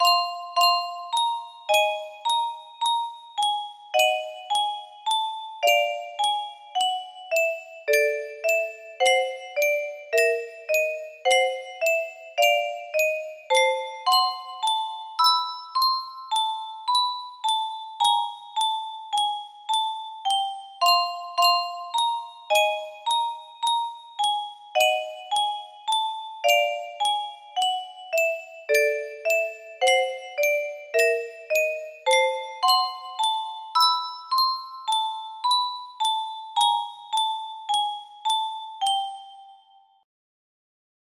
Dovewing's Leitmotif music box melody